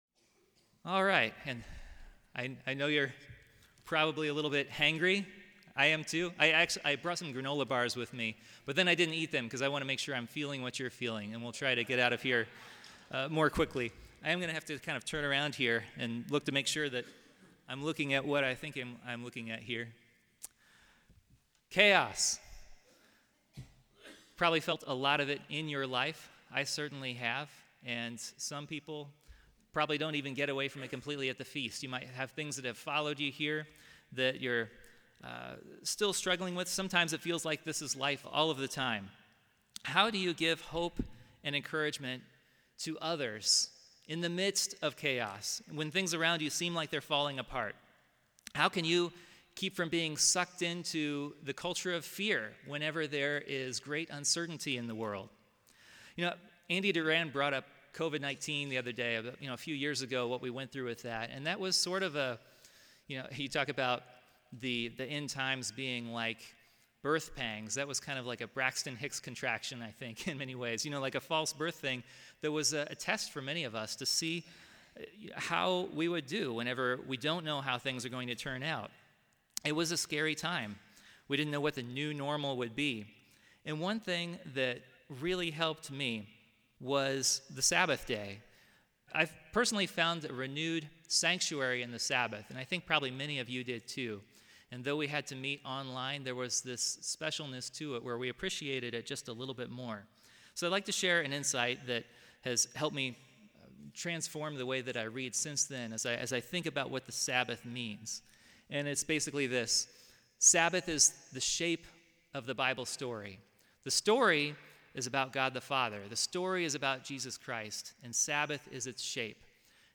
Let's follow the Biblical thread of sevens to see how it builds a mosaic portrait of the end of history as we know it: a time when God dwells among His complete, unified family. NOTE: This was an afternoon Bible presentation given at the Feast of Tabernacles in Jekyll Island.
This sermon was given at the Jekyll Island, Georgia 2023 Feast site.